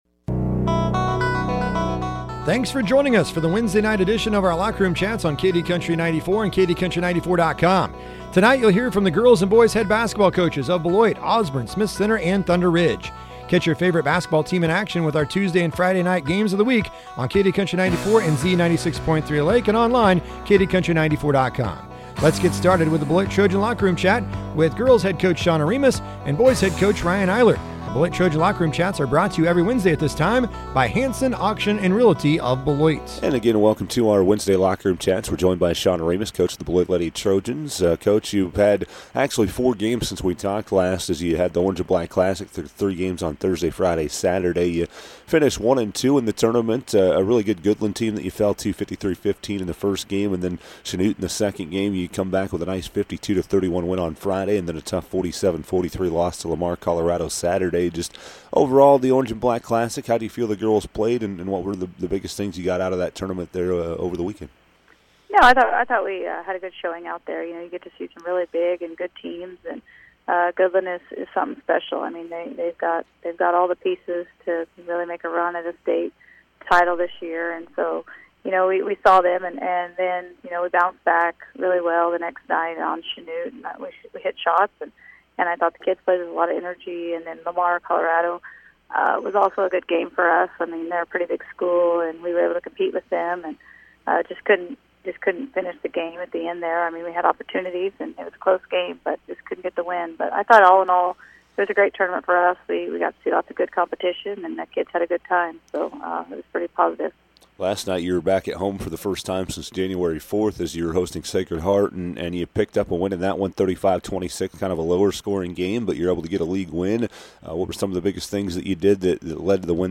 talks with the head basketball coaches